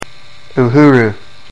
you-HOO-roo).